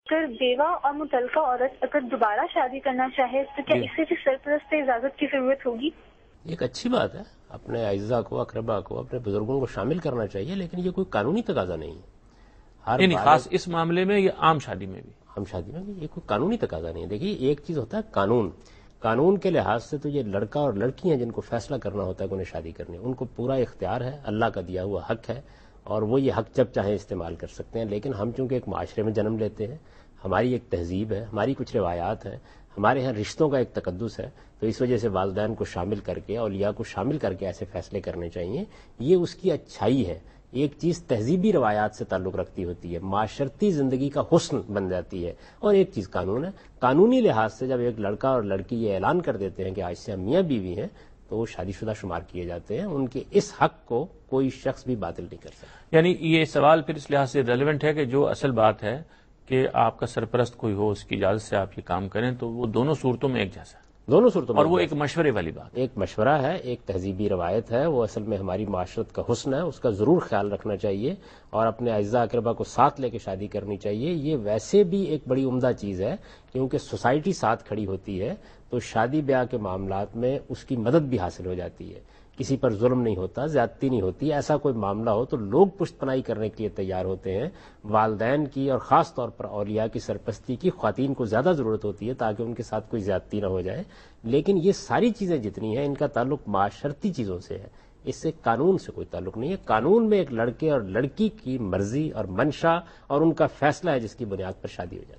Javed Ahmad Ghamidi answers a question about "Re-Marriage of a Divorced or Widow Woman and Permission of Guardian " in program Deen o Daanish on Dunya News.
جاوید احمد غامدی دنیا نیوز کے پروگرام دین و دانش میں بیوہ یا متلقہ عورت کا دوبارہ نکاح اور سرپرست کی اجازت سے متعلق ایک سوال کا جواب دے رہے ہیں۔